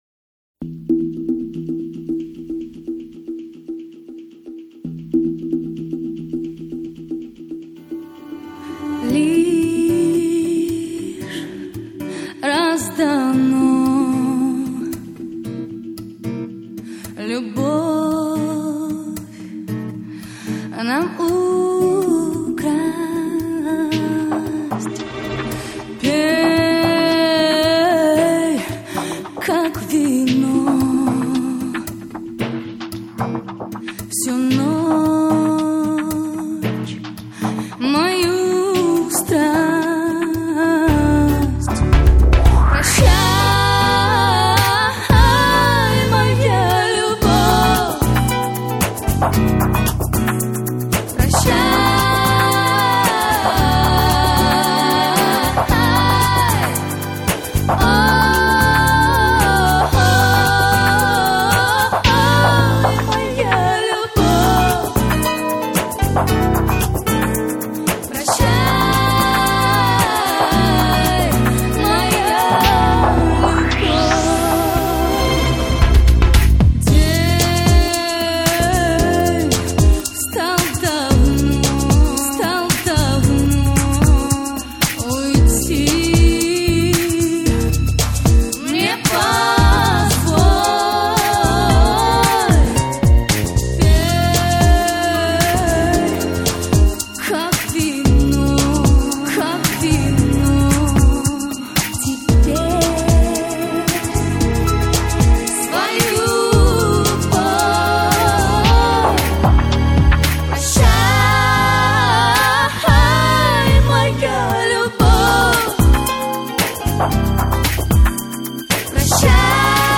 вокал
гитара